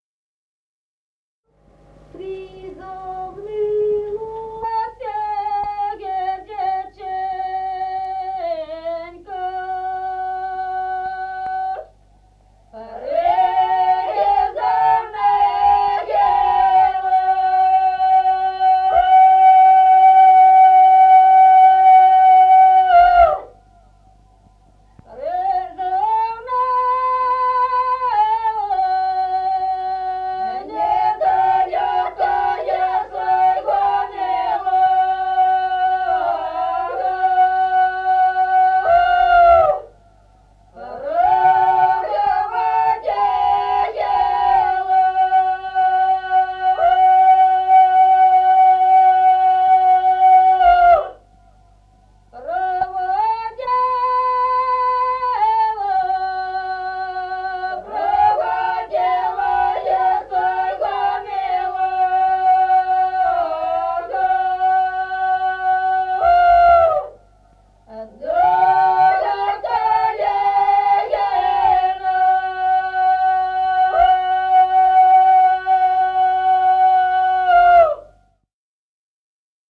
Песни села Остроглядово. Призавныло сердеченько (жнивная).